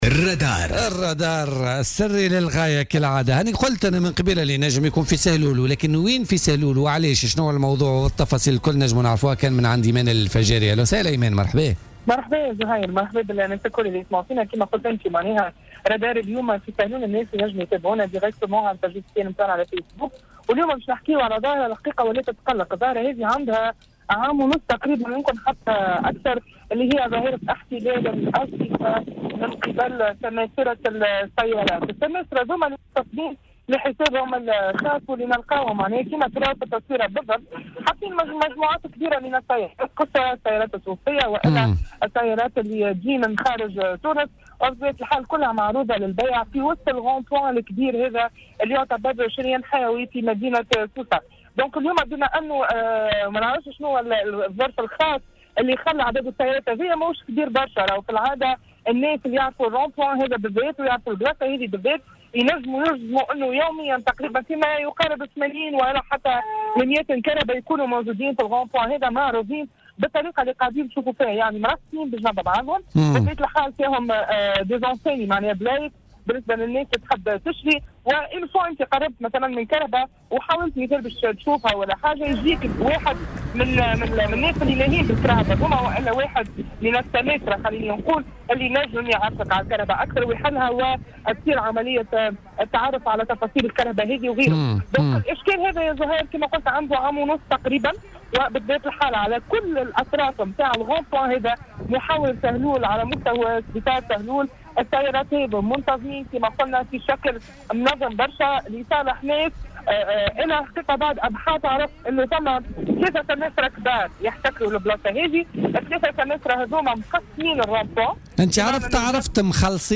تنقل فريق "الردار" لـ "الجوهرة أف أم" اليوم الاثنين إلى محول سهلول بسوسة وعاين ظاهرة احتلال الأرصفة من طرف سماسرة السيارات.